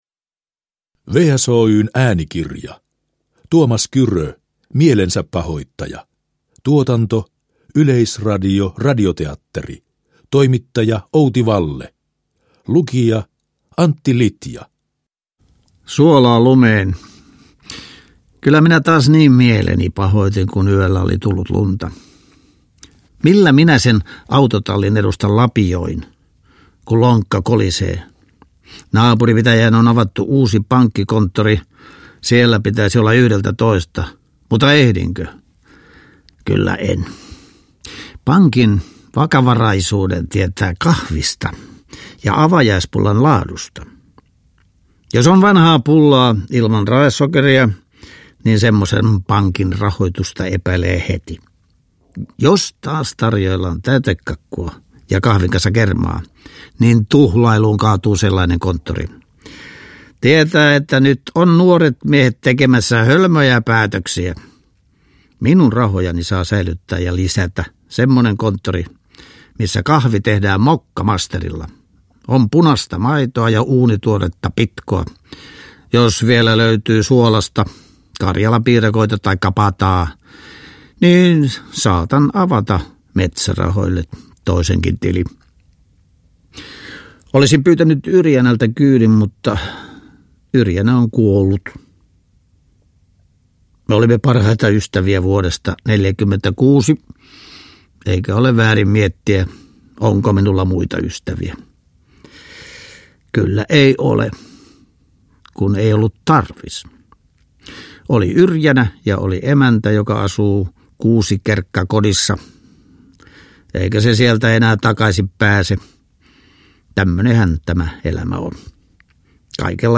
Mielensäpahoittaja – Ljudbok
Uppläsare: Antti Litja